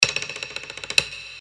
• Качество: 320, Stereo
без слов
Как будто кто чешет клюв о деревяшку